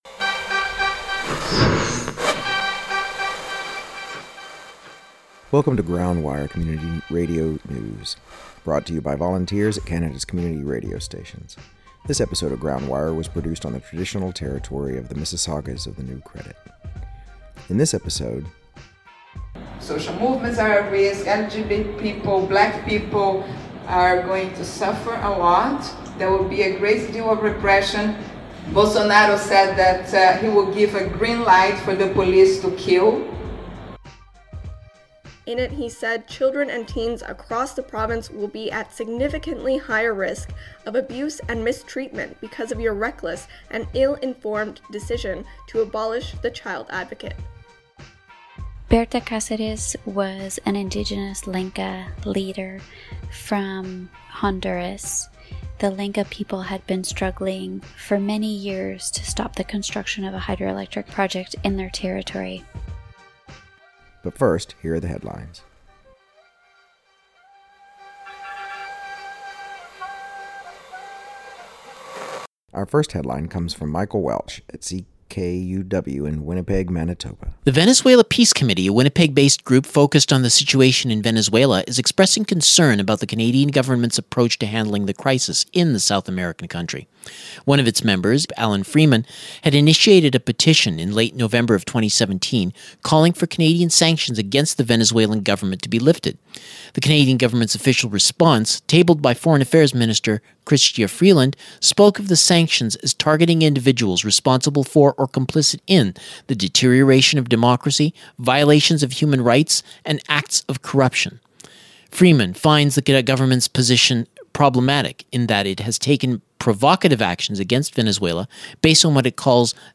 Community Radio News from Coast to Coast to Coast